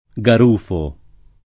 Garufo [ g ar 2 fo ]